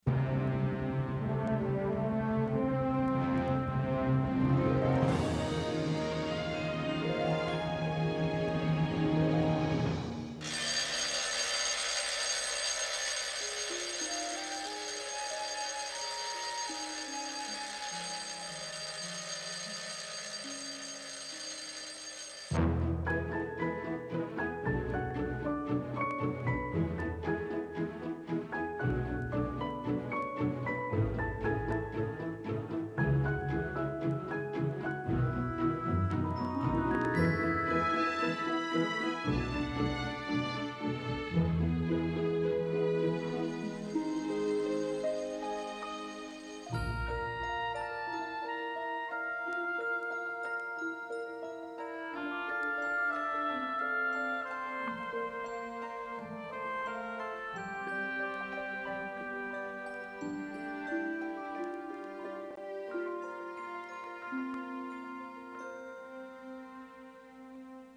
le générique d'ouverture de l'épisode musical